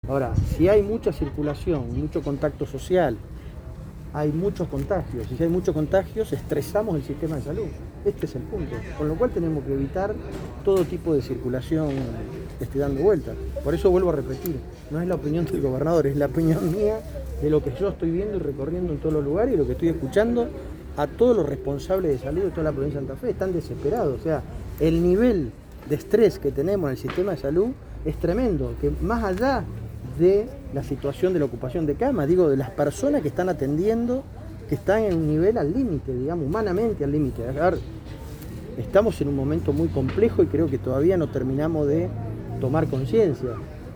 Así lo planteó el senador nacional durante su visita por el operativo de vacunación que lleva adelante el gobierno provincial en el predio de la Esquina Encendida de la Ciudad de Santa Fe.
Audio-Mirabella_vacunatorio_18-de-Mayo_parte2.mp3